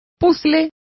Complete with pronunciation of the translation of jigsaws.